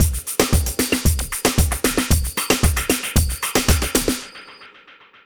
Ala Brzl 2 Drumz 2.wav